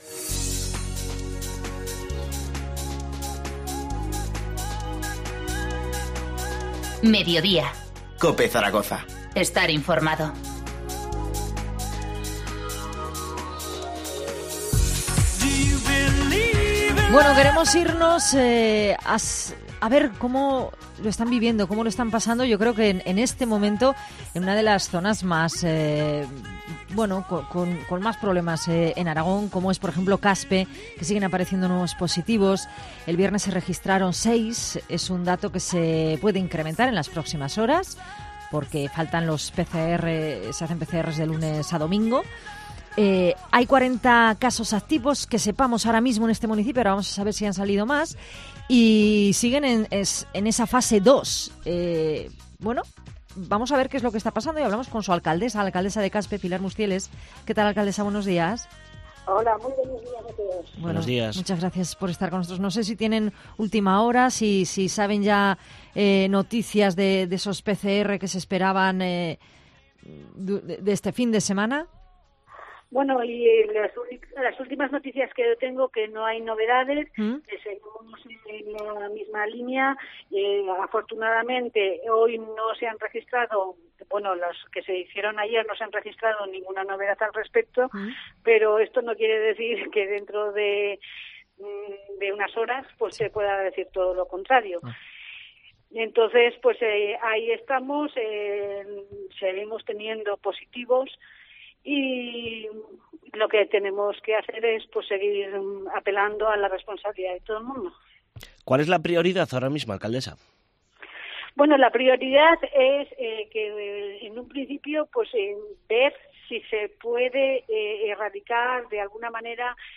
Entrevista a Pilar Mustieles, alcaldesa de Caspe